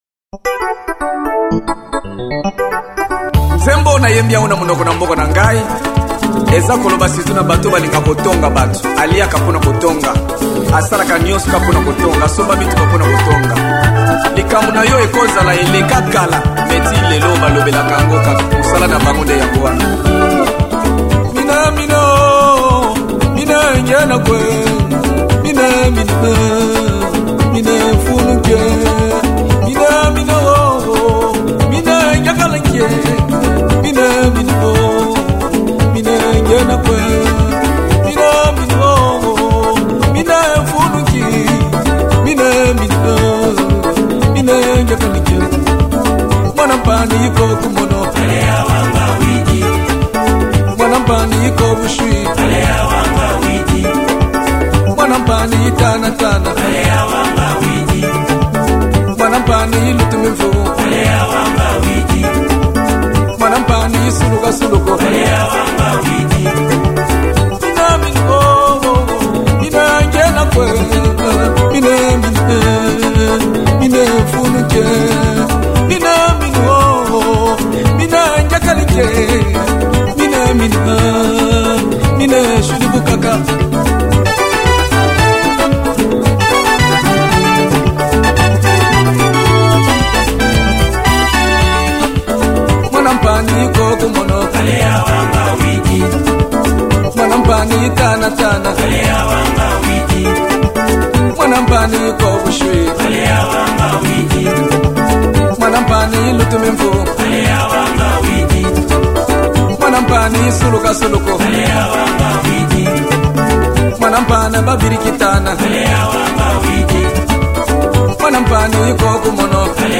Gospel 2008